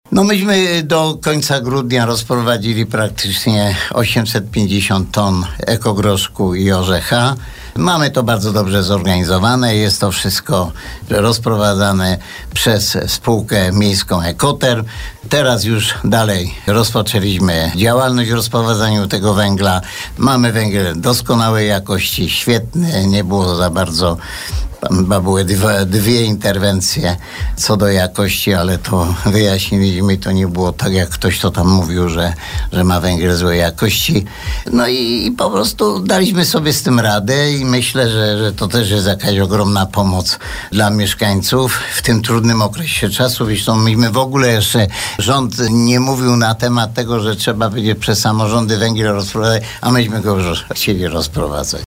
Burmistrz Antoni Szalgor tak podsumowywał pierwszą turę dystrybucji na naszej antenie.